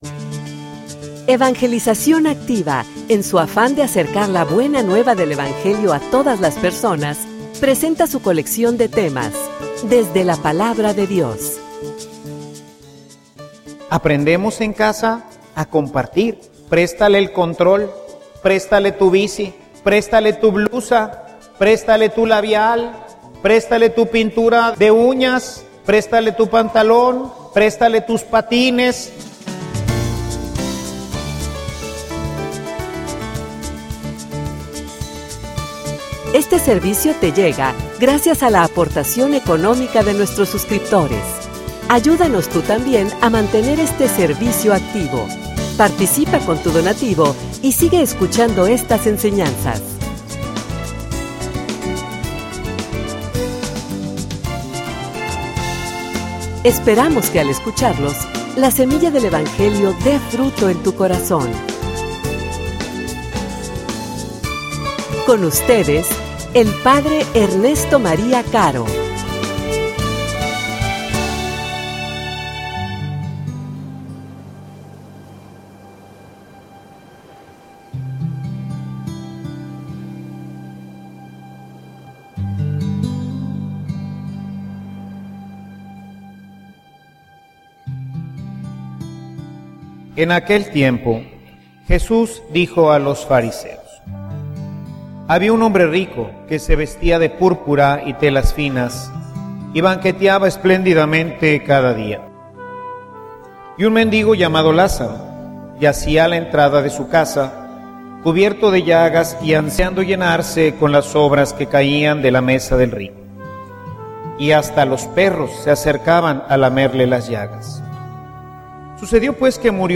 homilia_Aprende_a_compartir.mp3